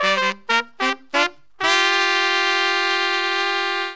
HORN RIFF 13.wav